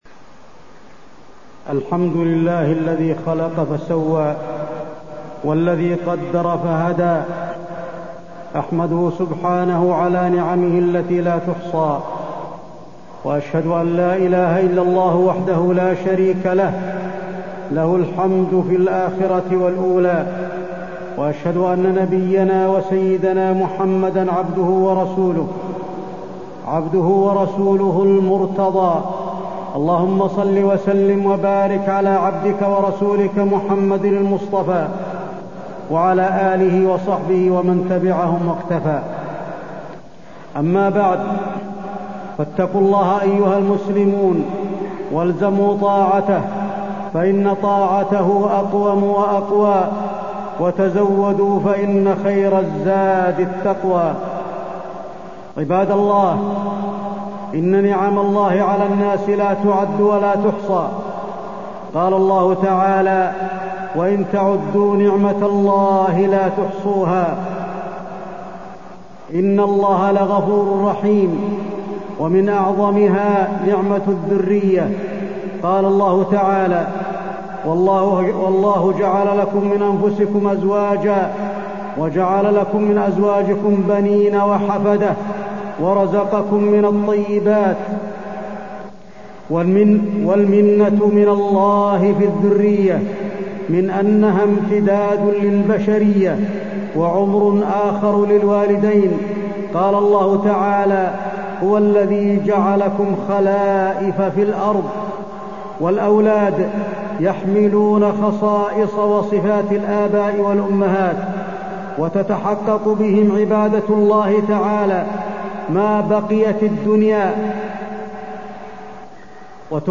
تاريخ النشر ١٩ ربيع الأول ١٤٢٣ هـ المكان: المسجد النبوي الشيخ: فضيلة الشيخ د. علي بن عبدالرحمن الحذيفي فضيلة الشيخ د. علي بن عبدالرحمن الحذيفي نعمة الأبناء وأمانة تربيتهم